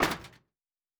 Metal Box Impact 1_2.wav